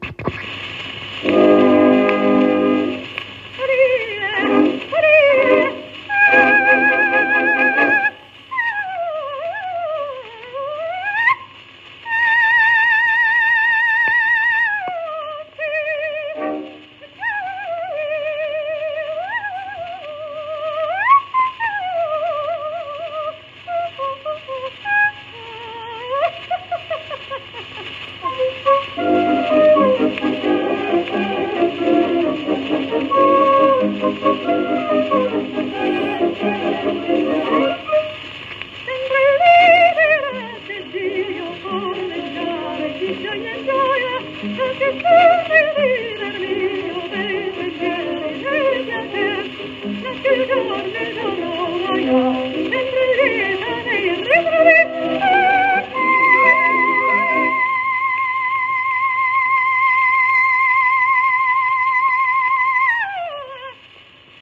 w/オーケストラ
10インチ 片面盤
旧 旧吹込みの略、電気録音以前の機械式録音盤（ラッパ吹込み）